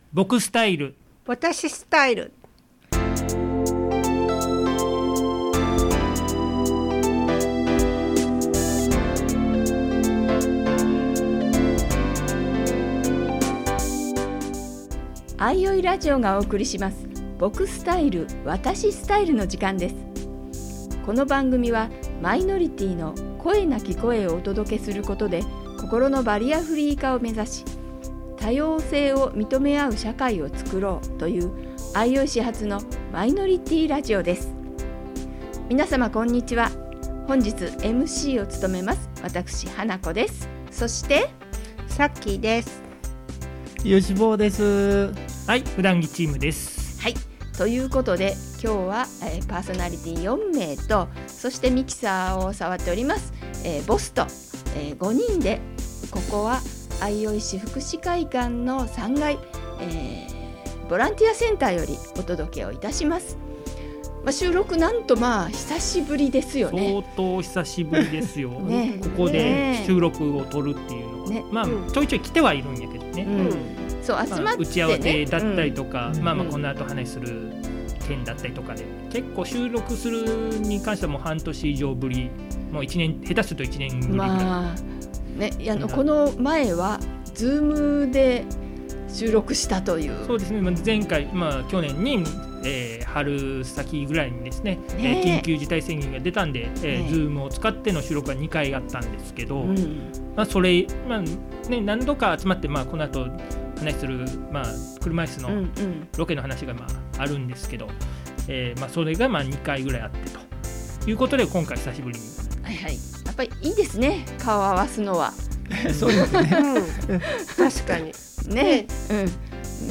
収録後記 久々の福祉会館での対面収録！
場所：相生市総合福祉会館